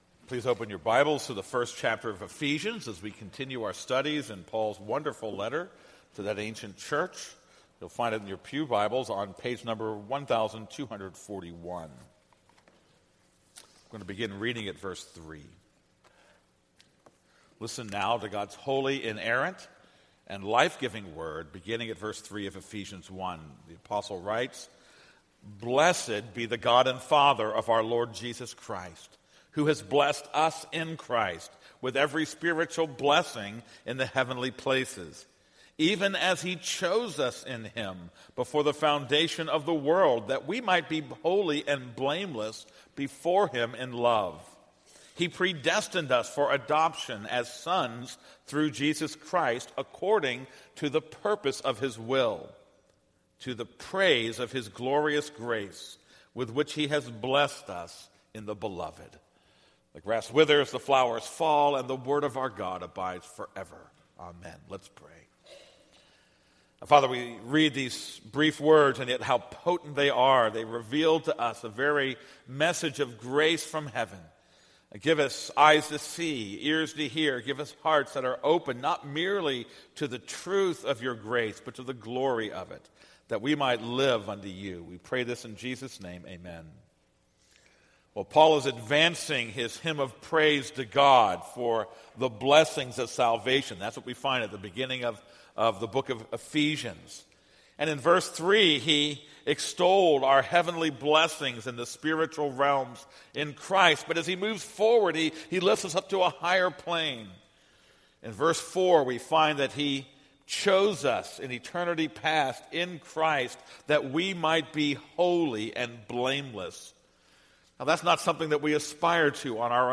This is a sermon on Ephesians 1:6.